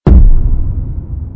AK_Boom2.wav